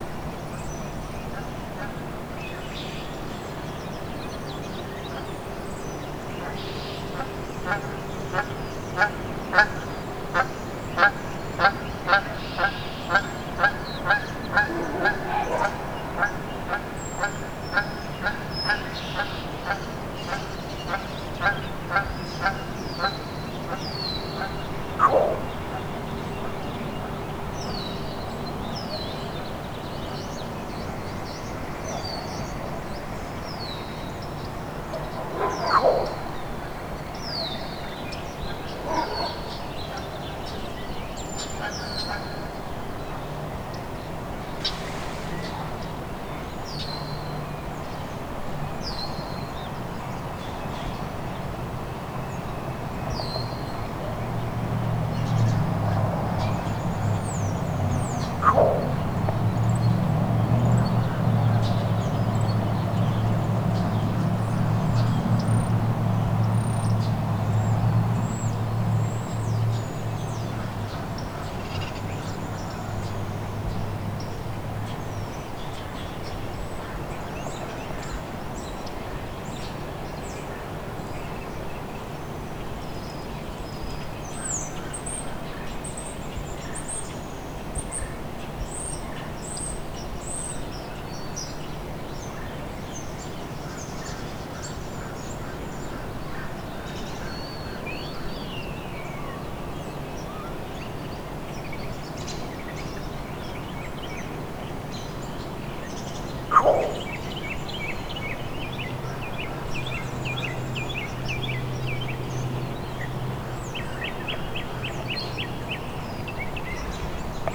Here’s the  advertising call, often described as a growl. Note there are 4 altogether and that they follow the honks.
green-heron-advertising.wav